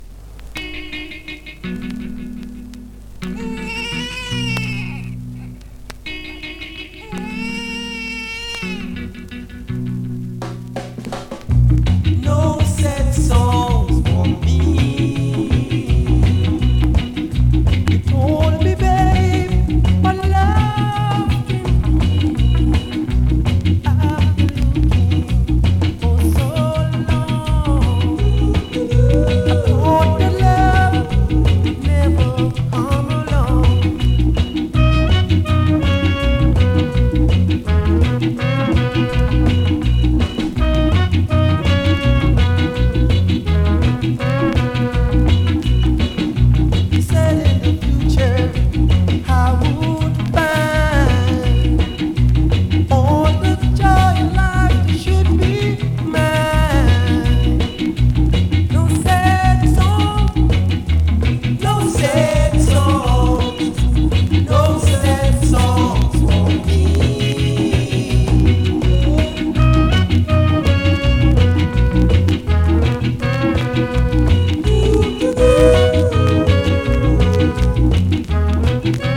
※後半クモリ有、チリノイズ強めです